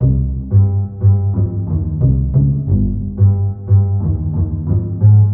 描述：大提琴和弦
Tag: 85 bpm Hip Hop Loops Piano Loops 1.90 MB wav Key : D